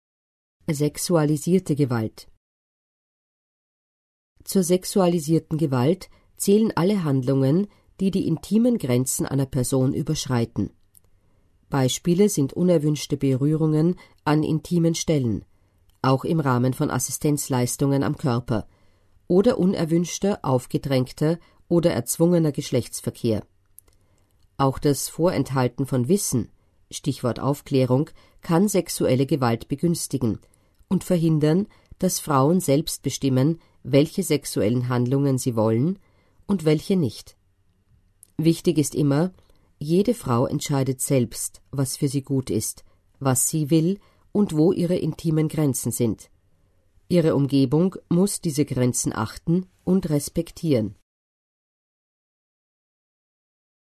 Hier finden Sie die österreichische Broschüre für Frauen mit Behinderungen als Audioversion: „Gewalt, was kann ich tun? Informationen für Frauen mit Behinderungen.“